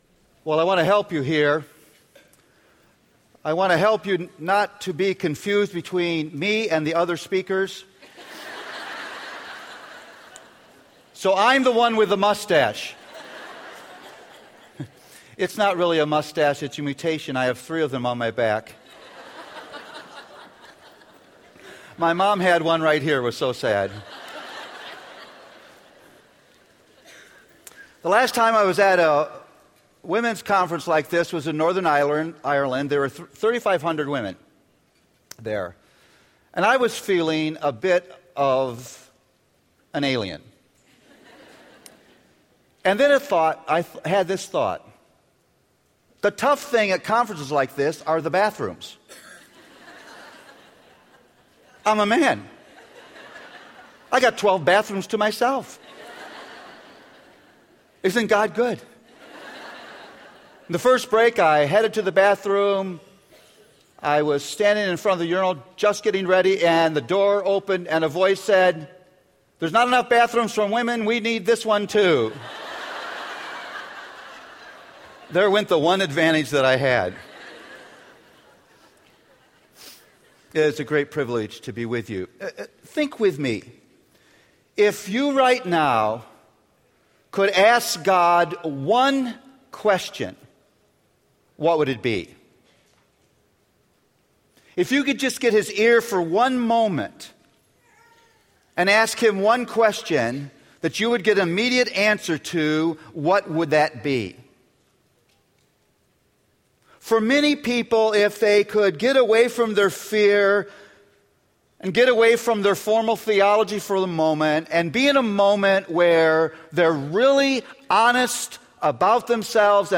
R13-Plenary-Session2-PDT.mp3